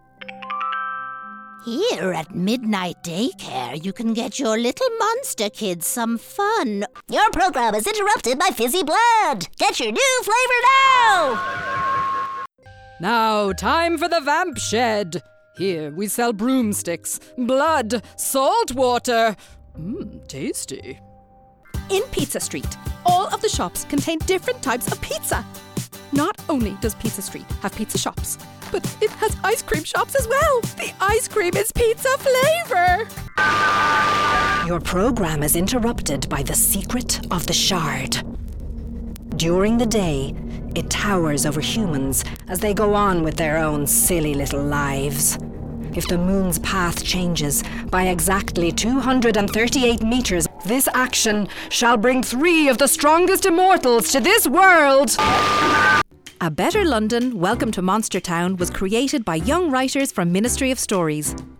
Animation Showreel
Female
Irish
Southern Irish
Soft
Upbeat
Confident